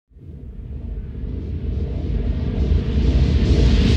دانلود آهنگ طوفان 28 از افکت صوتی طبیعت و محیط
جلوه های صوتی
دانلود صدای طوفان 28 از ساعد نیوز با لینک مستقیم و کیفیت بالا